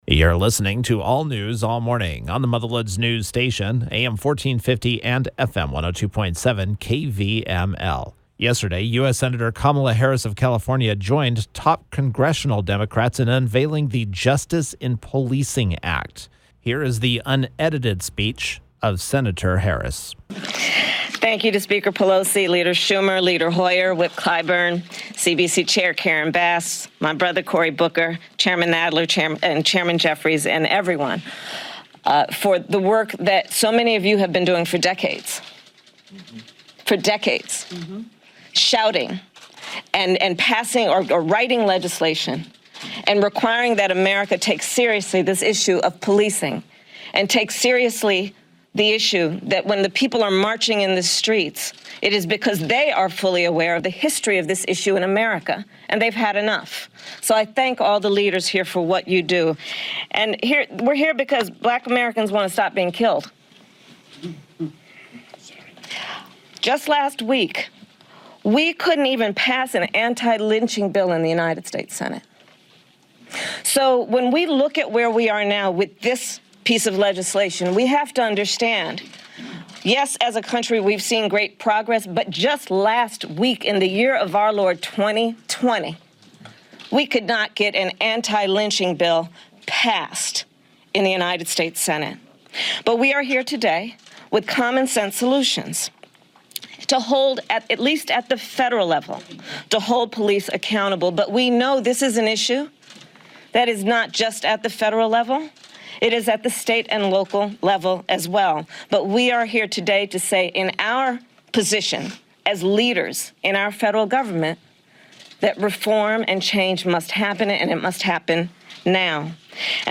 Harris was Thursday’s KVML “Newsmaker of the Day”. Here are her words: